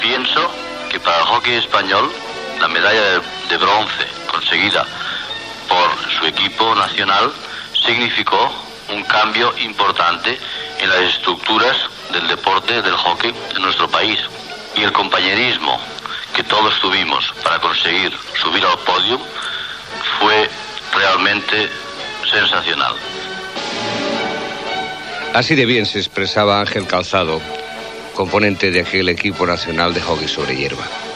Divulgació
Fragment extret del programa "La radio con botas", emès per Radio 5 l'any 1991